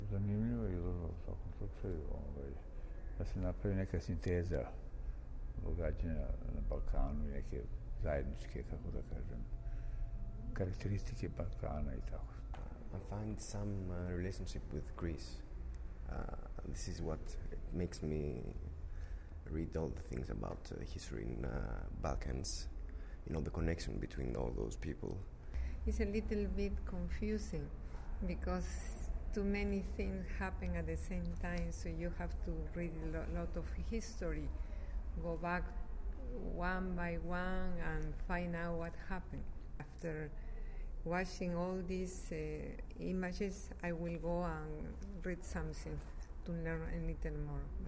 Utisci posetilaca izložbe